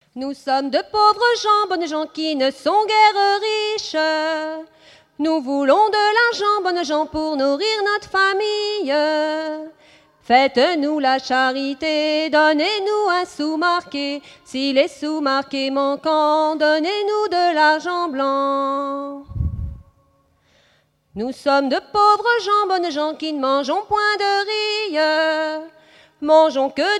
Genre laisse
Festival de la chanson traditionnelle - chanteurs des cantons de Vendée
Pièce musicale inédite